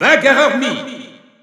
Announcer pronouncing Mii Brawler's name with masculine pronouns in French.
Mii_Brawler_M_French_Announcer_SSBU.wav